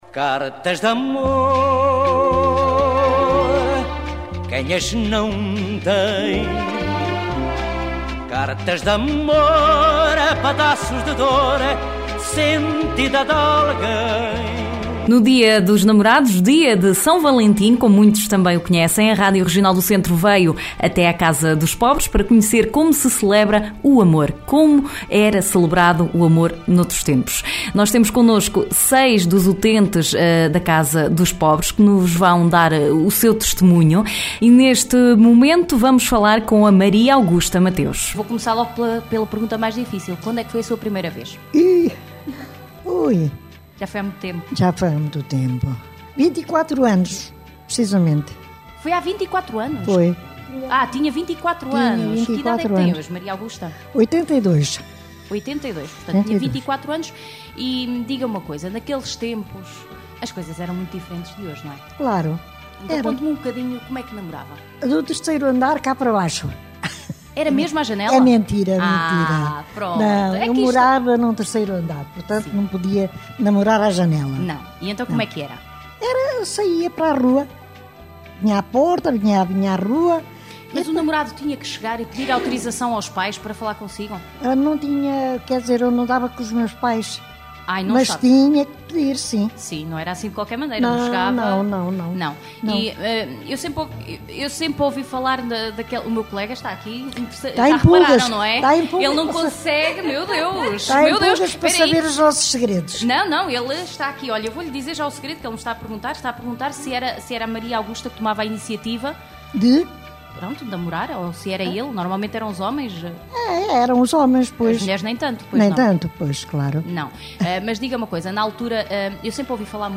A Regional do Centro foi à Casa dos Pobres, em Coimbra, para saber junto de alguns utentes como se celebrava o amor há algumas décadas atrás. Oiça aqui um dos testemunhos.